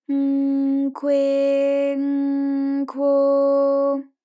ALYS-DB-001-JPN - First Japanese UTAU vocal library of ALYS.
kw_N_kwe_N_kwo.wav